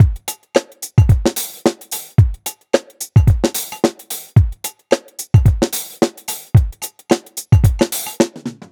Unison Funk - 8 - 110bpm.wav